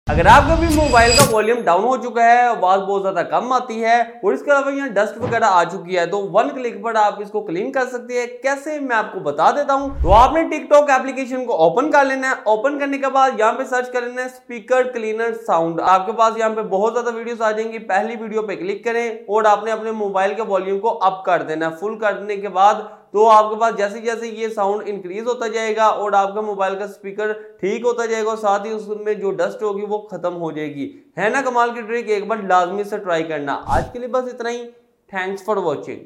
Speaker Cleaner Sound 🔥 Clear Sound Effects Free Download